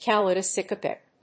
• / kəlὰɪdəskάpɪk(米国英語)